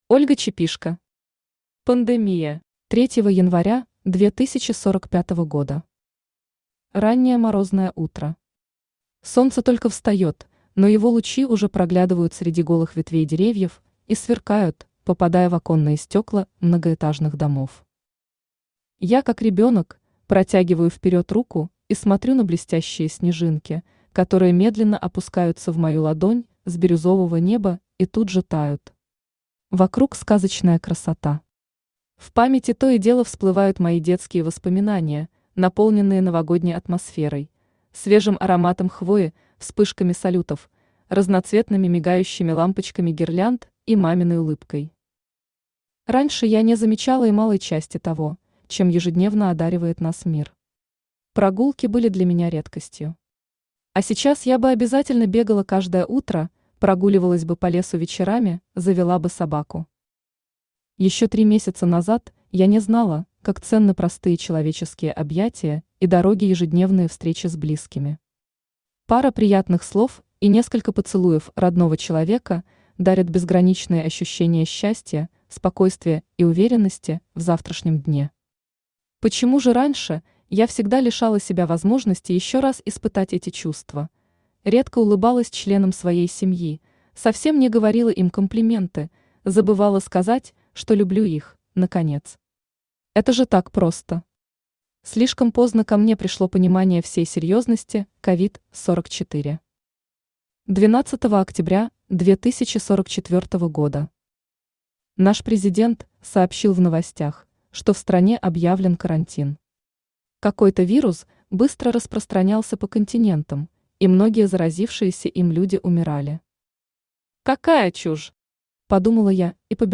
Аудиокнига Пандемия | Библиотека аудиокниг
Aудиокнига Пандемия Автор Ольга Чепишко Читает аудиокнигу Авточтец ЛитРес.